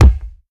ball-hit.ogg